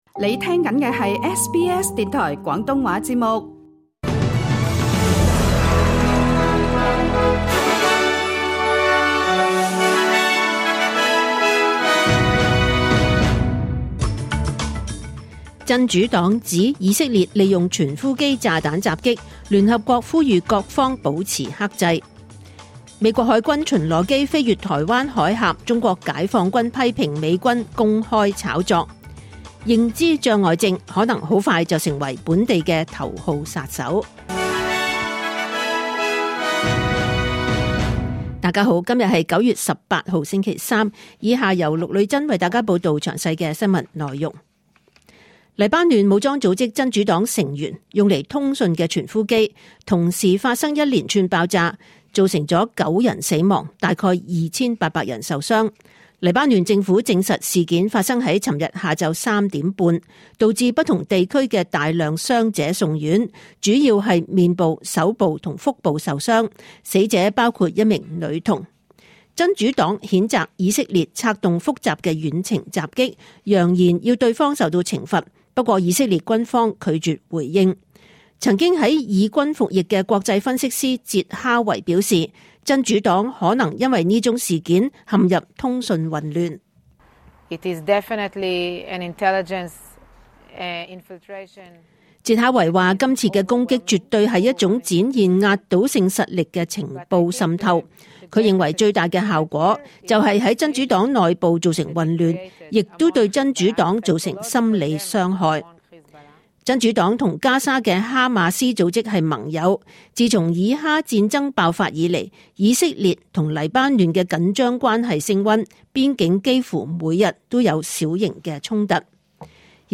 2024 年 9 月 18 日 SBS 廣東話節目詳盡早晨新聞報道。